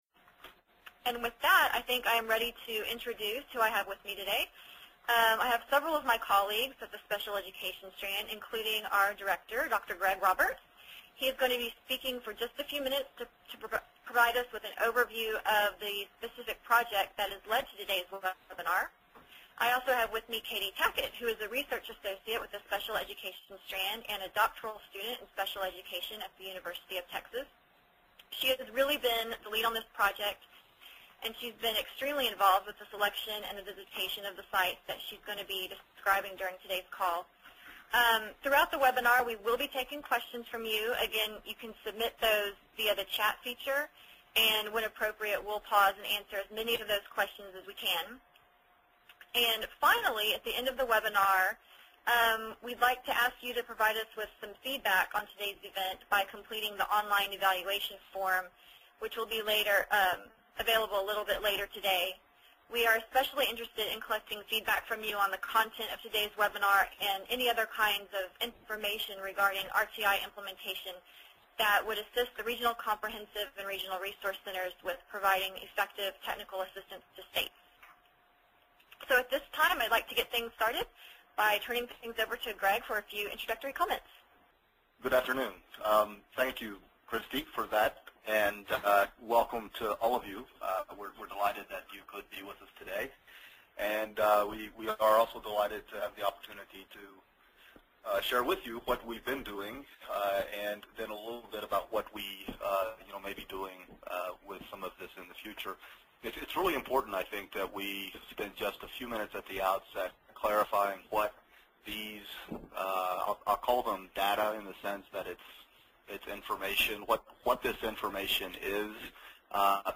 RTI_Dec_4_Webinar_Audio-edited.mp3